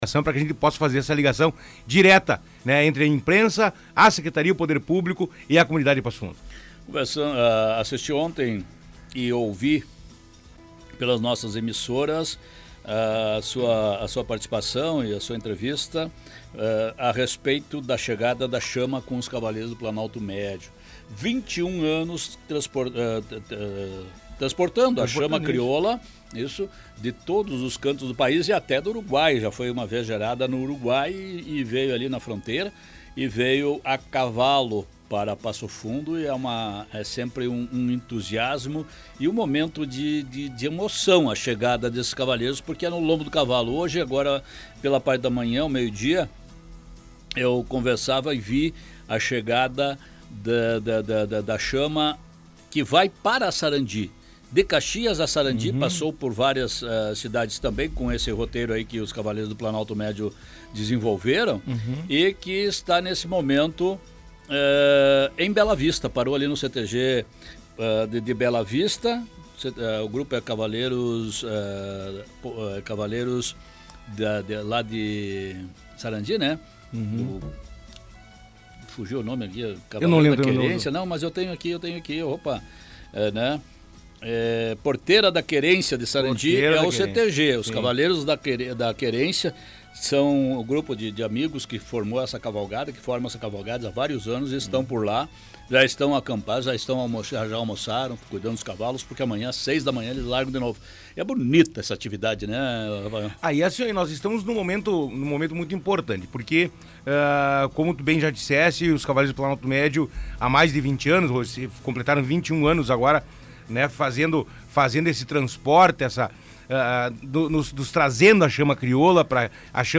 O secretário municipal de Cultura Rafael Bortoluzzi foi entrevistado nessa segunda-feira, 25, no programa A Primeira Hora da Rádio Planalto News (92.1).